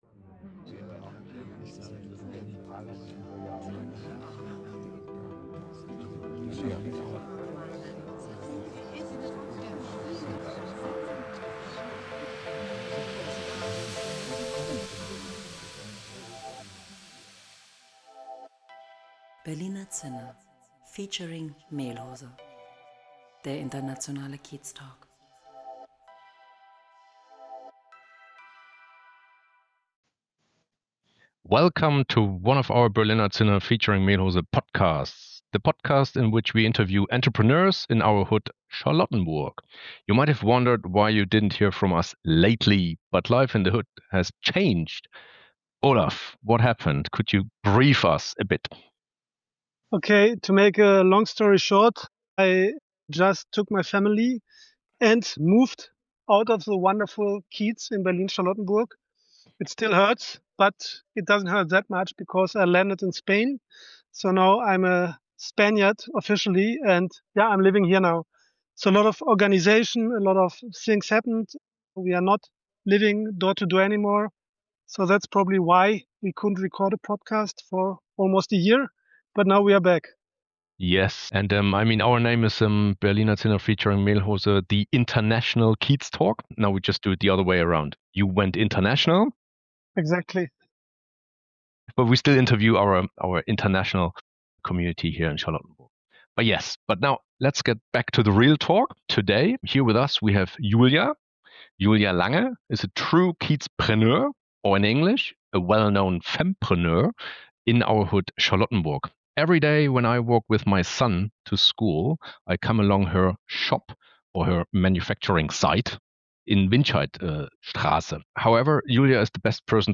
A conversation about craft, community and listening to your heart.